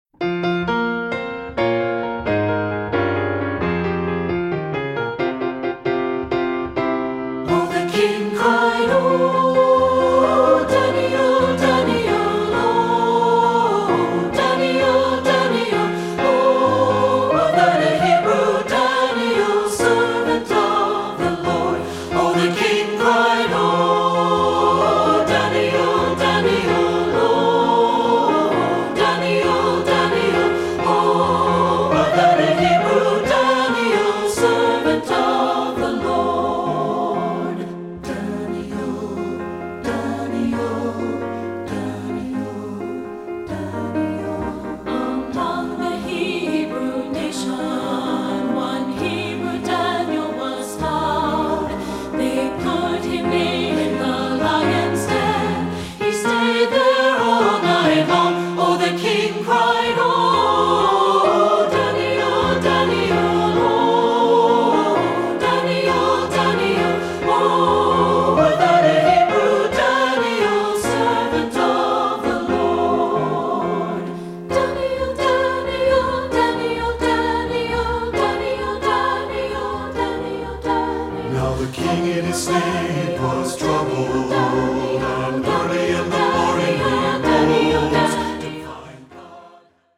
Choral Spiritual
Traditional Spiritual
SAB